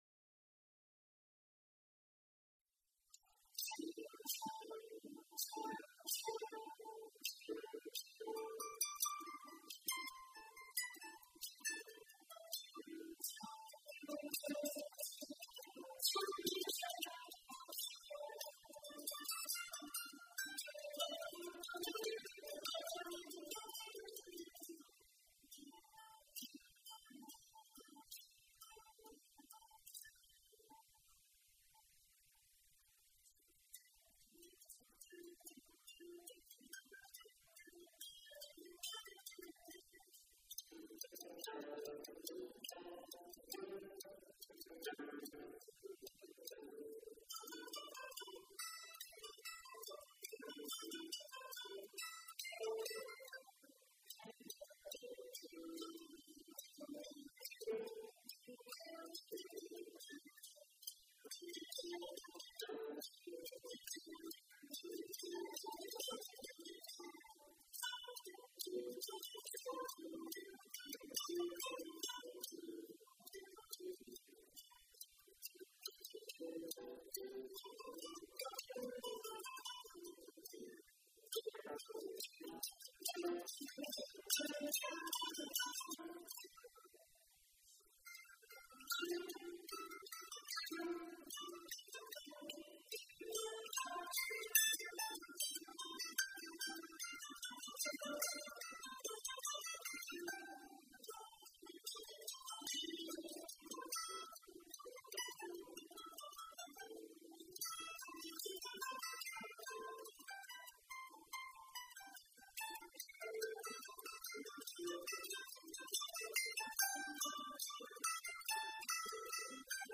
Clark's Ramble (live from CMSA Santa Rosa, November 2018)
While written for two mandolins
octave mandolin
We had rehearsed a couple of times and had practiced in advance but the fun of actually playing it for an appreciative audience (watch your volume control at the end) allowed us to take a few liberties (mostly unintentional) with the written music. At one point I believe we even fall out of sync for a few measures, resulting in some fun new music.